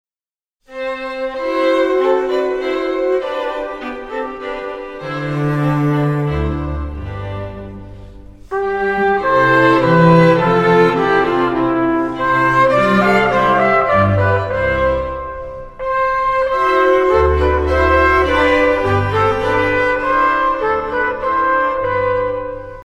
Trompete
Violine
Viola
Violoncello
Contrabass
in der Auferstehungskirche Neu-Rum